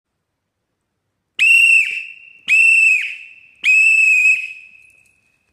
Écoute des signaux d’alarme👂📢🔥🚒⛈🔐
• les 3 coups de sifflets
sifflet-.mp3